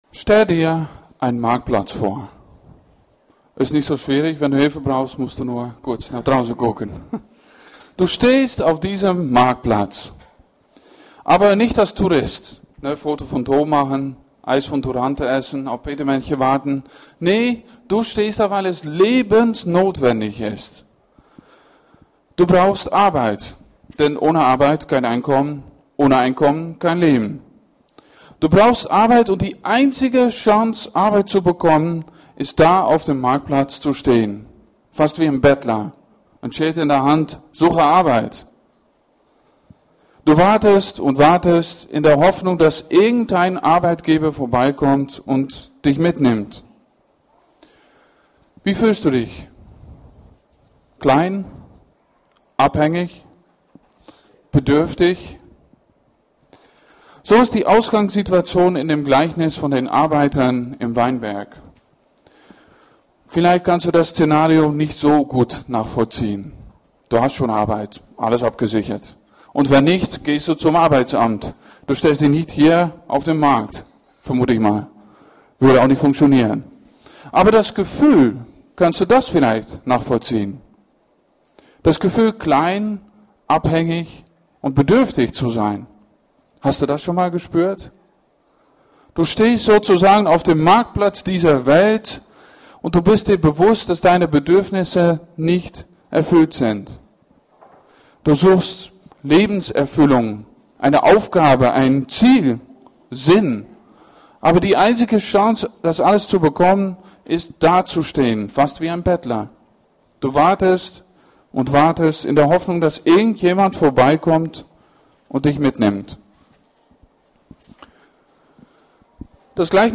Hier sind die Predigten der wöchentlichen Gottesdienste der FeG Schwerin.